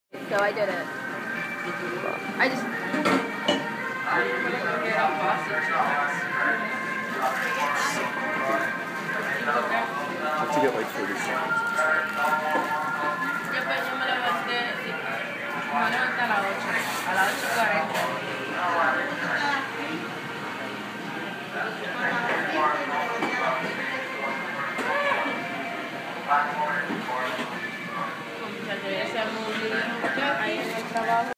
Field Recording #1: Starbucks in Penn Station
Waiting for my caffe mocha at Starbucks before city class on Saturday. Milk being steamed, coffee being poured, people chattering away, and announcements for the LIRR heard in the distance.
FieldRecording1.mp3